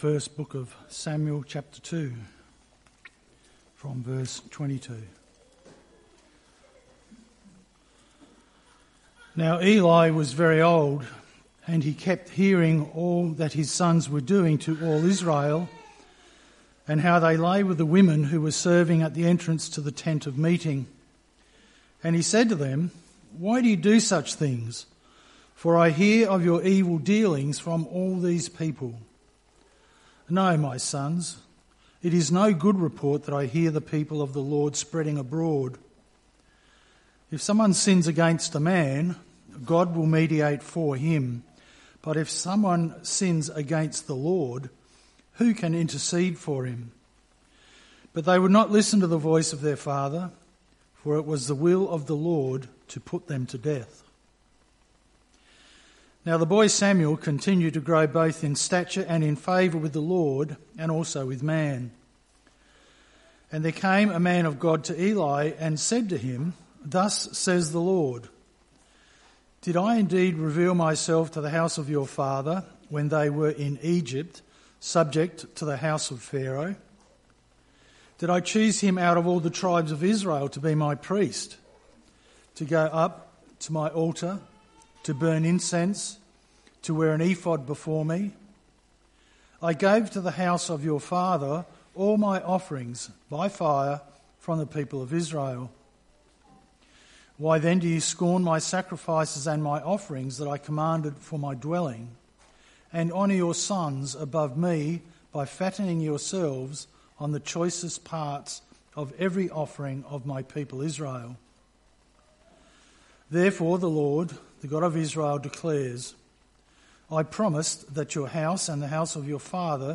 Oct 05, 2025 Ephesians 6:4 – Fathers who nurture MP3 SUBSCRIBE on iTunes(Podcast) Notes Sermons in this Series 5th October 2025 AM 1 Samuel 2:22-36 , Ephesians 6:4 Ephesians 6:4 – Fathers who nurture